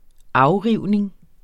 Udtale [ -ˌʁiwˀneŋ ]